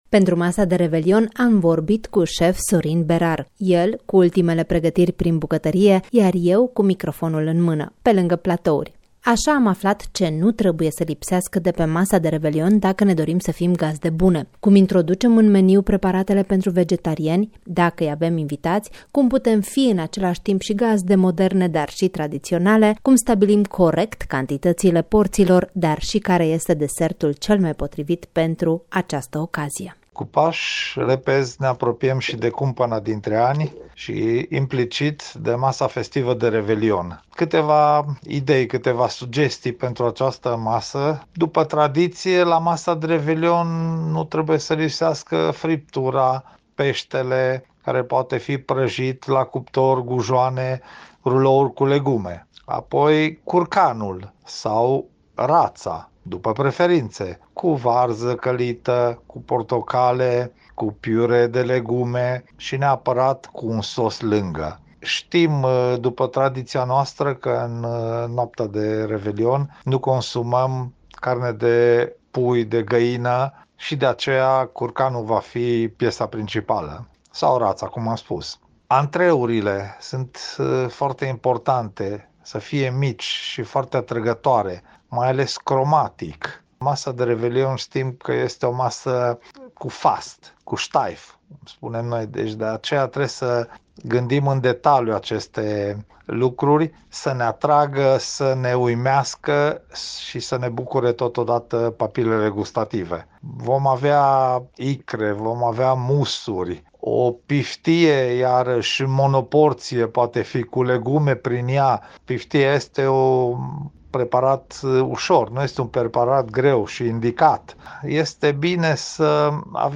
El cu ultimele pregătiri prin bucătărie, iar eu cu microfonul în mână, pe lângă platouri.